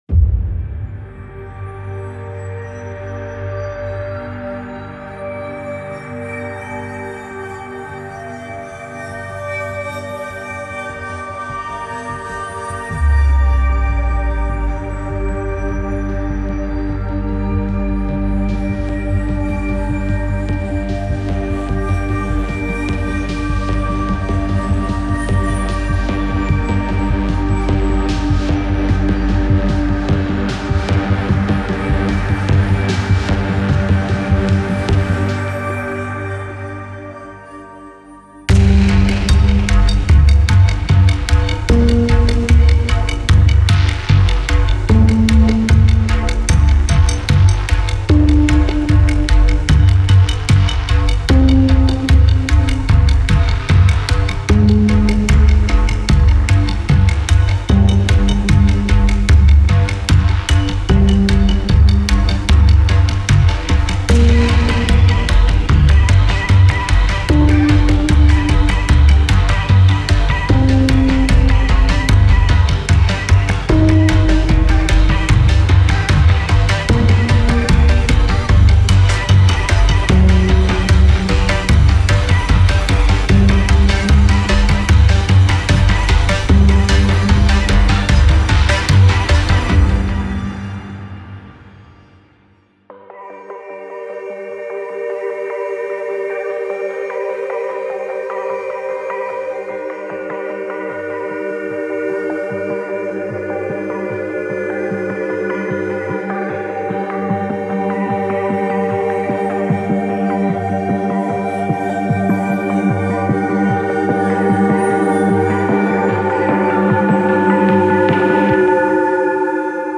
An ominous rumble swells from deep within.